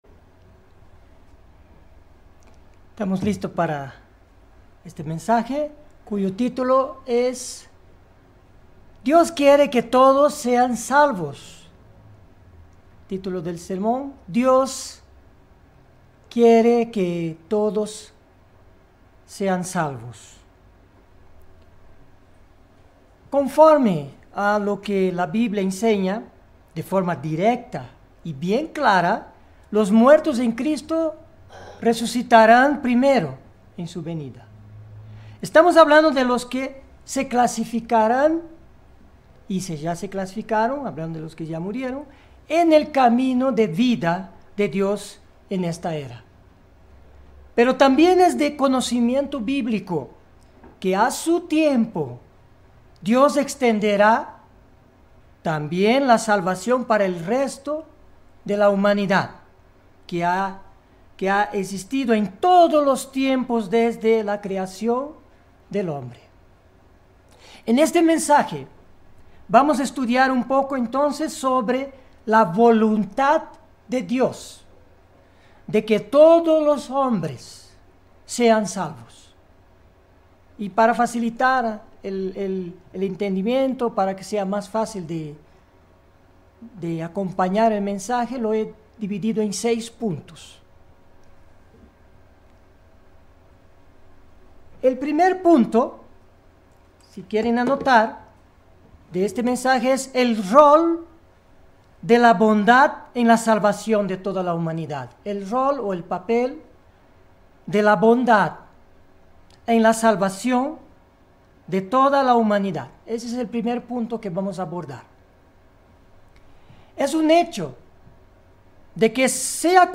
Sin embargo, Dios también extenderá su salvación para toda la humanidad de todos los tiempos. Mensaje entregado el 1 de junio de 2019.